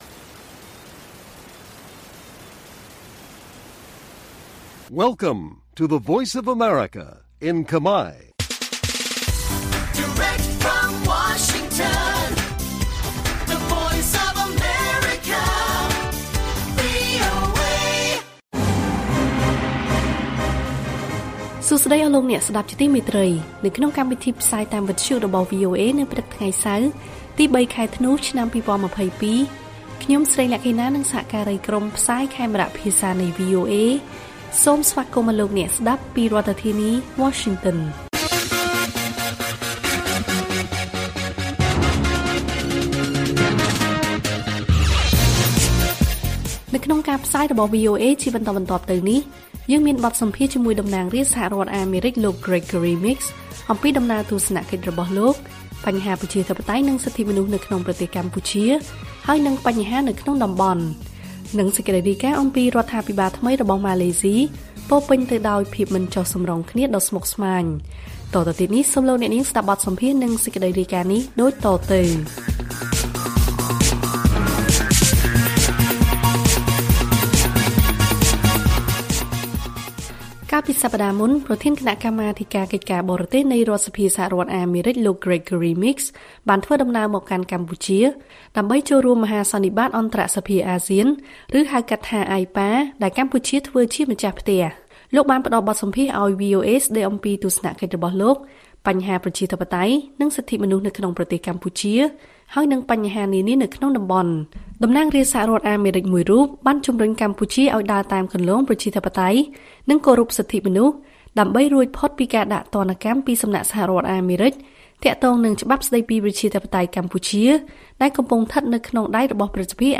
ព័ត៌មានពេលព្រឹក ៣ ធ្នូ៖ បទសម្ភាសជាមួយតំណាងរាស្ត្រសហរដ្ឋអាមេរិកលោក Gregory Meeks អំពីដំណើរទស្សនកិច្ចរបស់លោកនៅកម្ពុជា